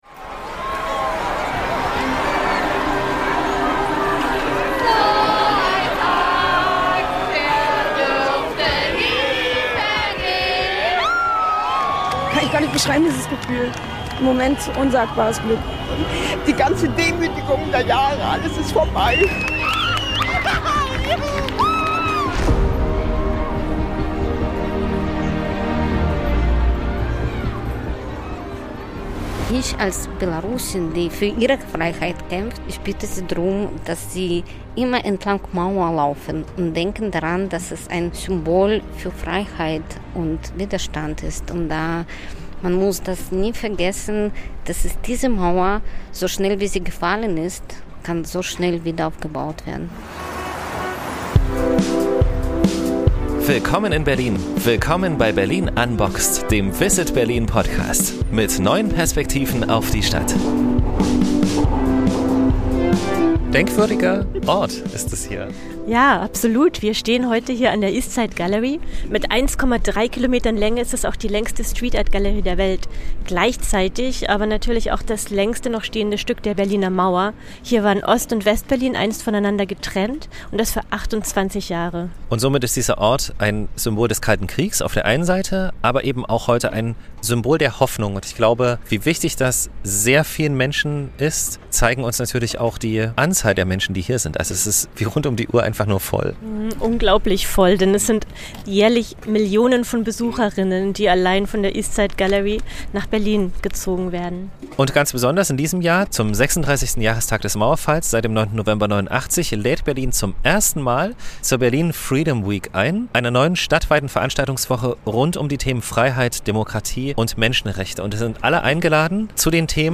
Aber was bedeutet es heute, Freiheit zu leben – und sie gegen Unterdrückung zu verteidigen? Anlässlich der ersten Berlin Freedom Week, einer stadtweiten Veranstaltungswoche rund um Freiheit und Demokratie, haben wir uns an der East Side Gallery mit zwei Menschen getroffen, deren Lebenswege die Freiheitskämpfe zweier Generationen spiegeln: Frank Ebert, ehemals Oppositioneller in der DDR und heute Berliner Beauftragter zur Aufarbeitung der SED-Diktatur.